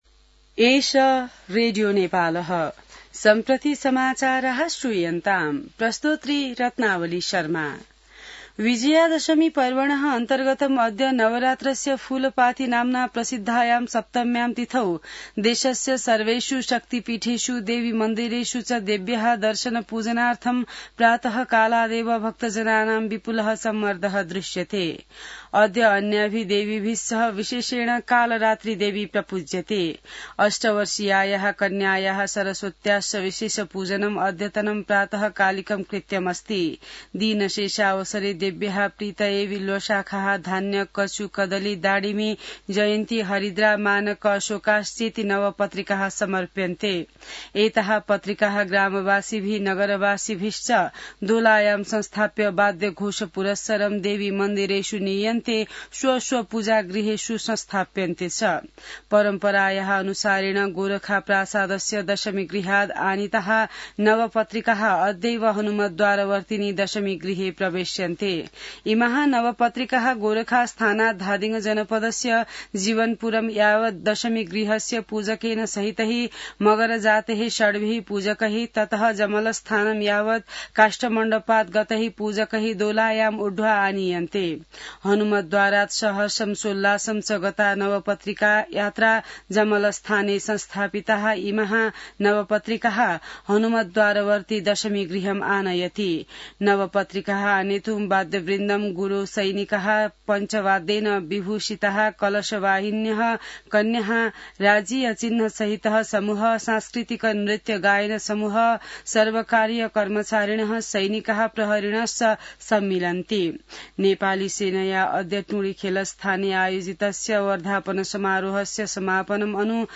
संस्कृत समाचार : १३ असोज , २०८२